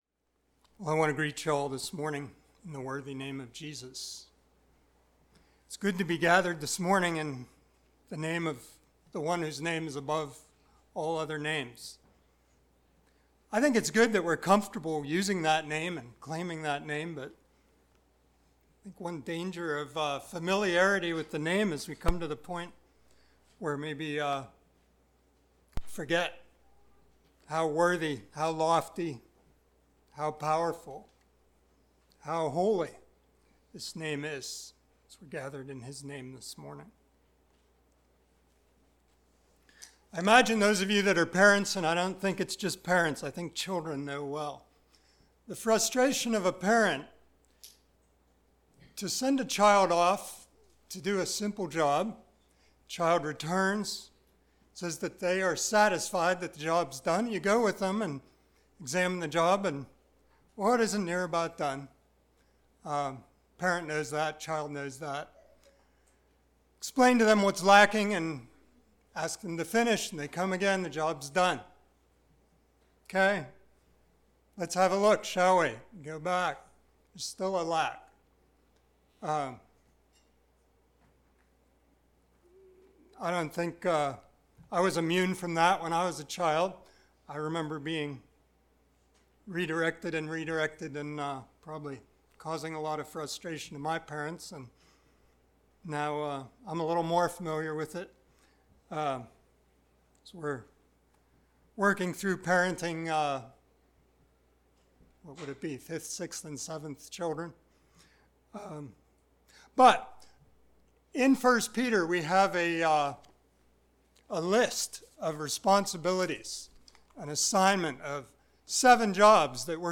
Passage: 2 Peter 1:1-11 Service Type: Sunday Morning Topics: Christian Graces , Knowledge , Virtue